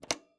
pgs/Assets/Audio/Sci-Fi Sounds/Mechanical/Device Toggle 14.wav
Device Toggle 14.wav